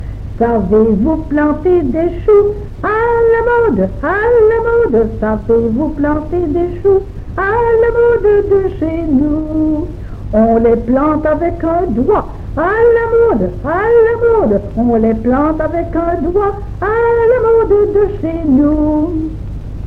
Genre : chant
Type : ronde, chanson à danser
Lieu d'enregistrement : Jolimont
Support : bande magnétique
Ronde.